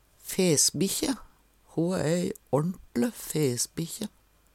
rn - lyden er ikkje rein rn -lyd ly til lydfil